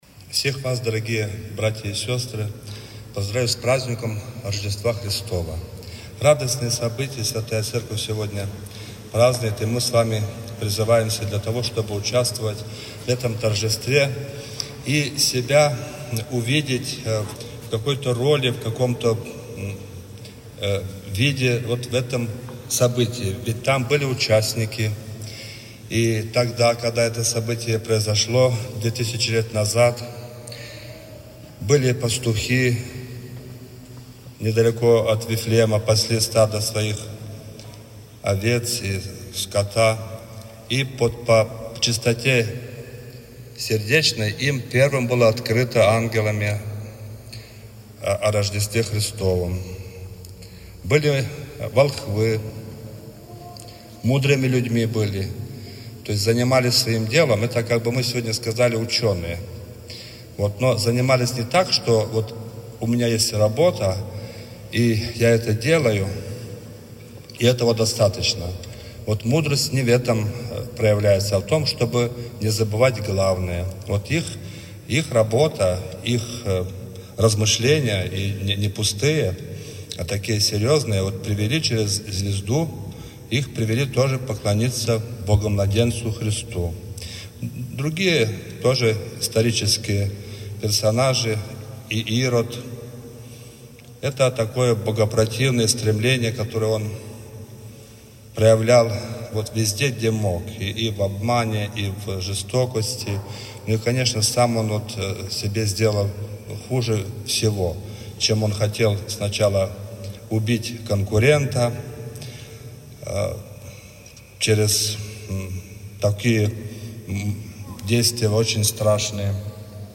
Слово